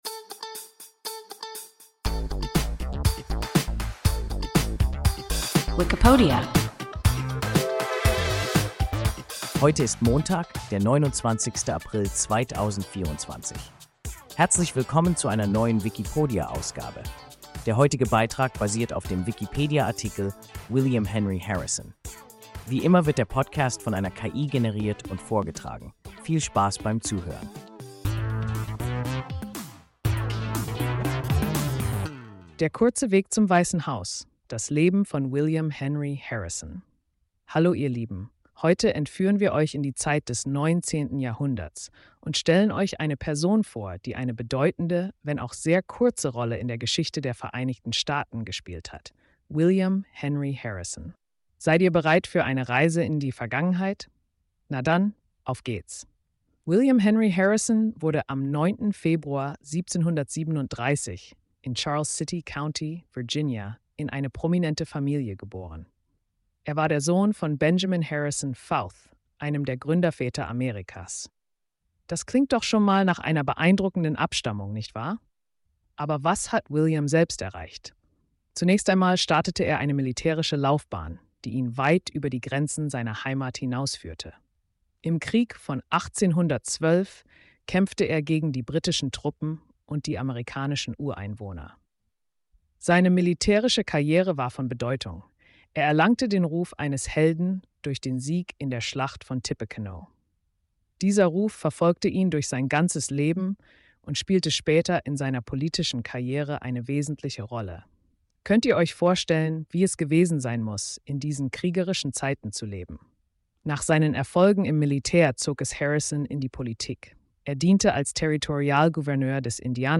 William Henry Harrison – WIKIPODIA – ein KI Podcast